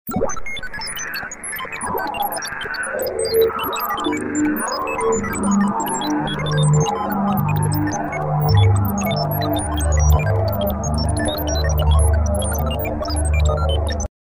科幻电脑音